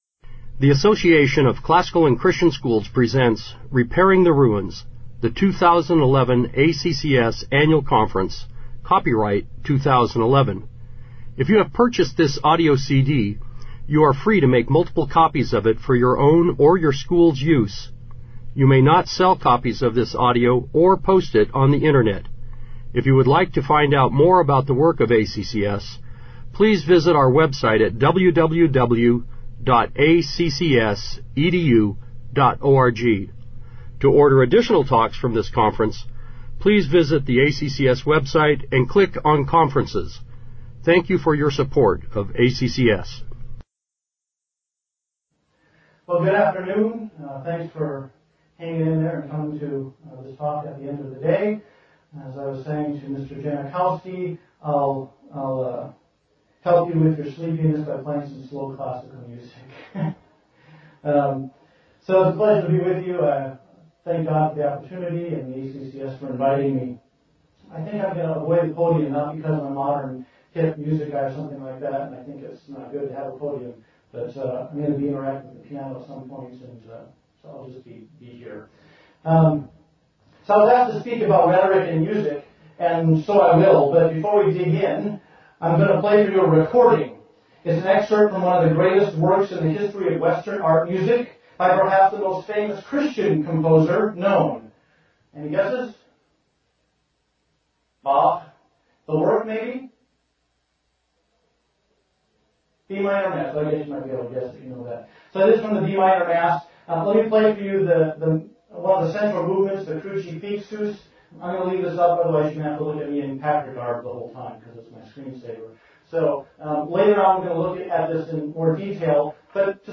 2011 Foundations Talk | 1:03:14 | All Grade Levels, Art & Music